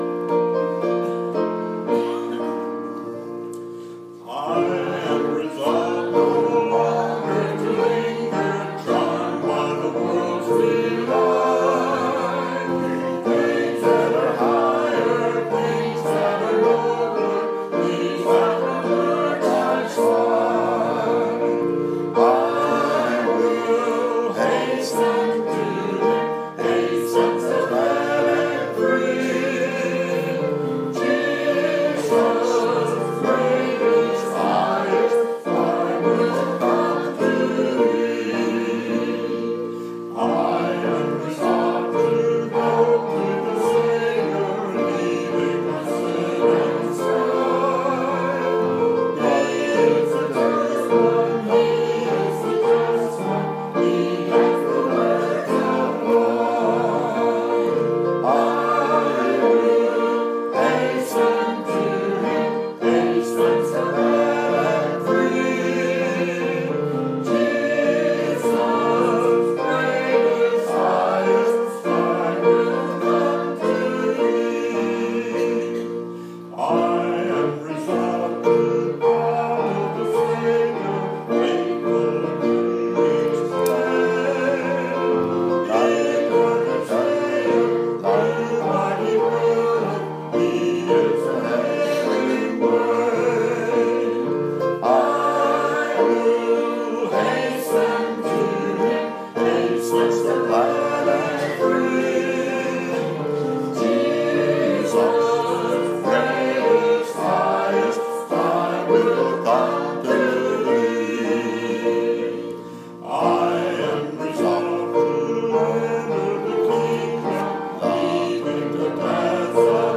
Preached to Riverview Baptist on May 21, 2017 at 798 Santa Fe Pike Columbia TN